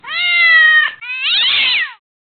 mp3 mačací rev